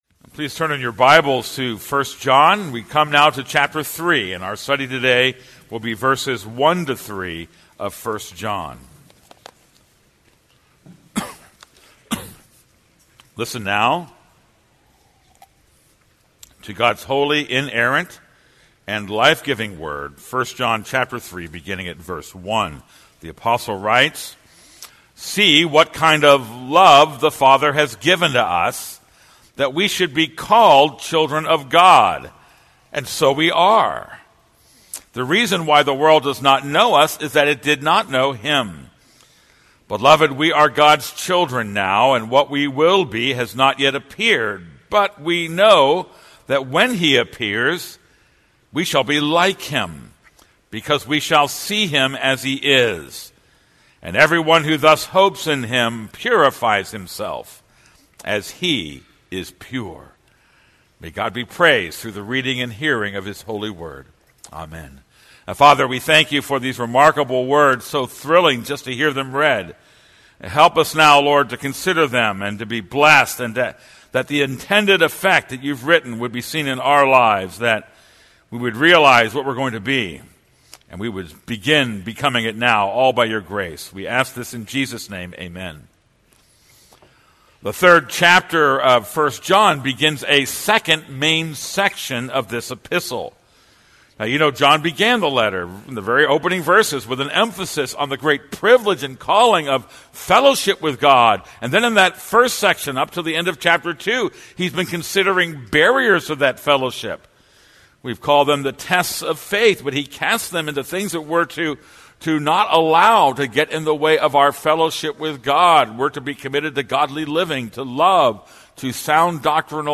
This is a sermon on 1 John 3:1-3.